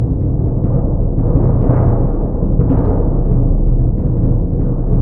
Index of /90_sSampleCDs/Roland LCDP03 Orchestral Perc/PRC_Orch Bs Drum/PRC_Orch BD Roll